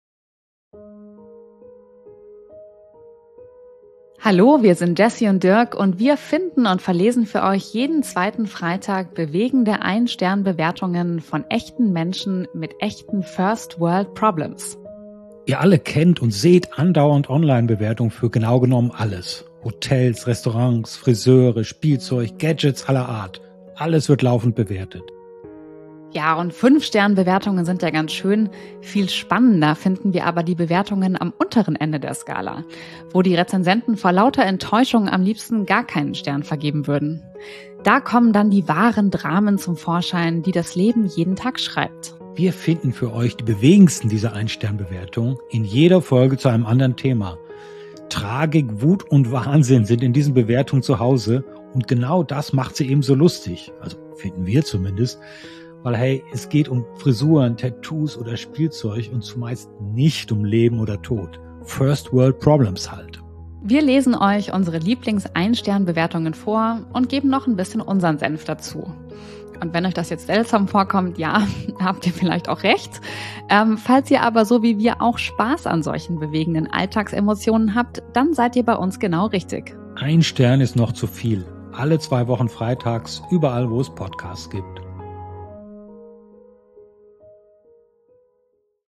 Wir finden und verlesen für euch bewegende Ein-Stern-Bewertungen von echten Menschen mit echten First-World-Problems.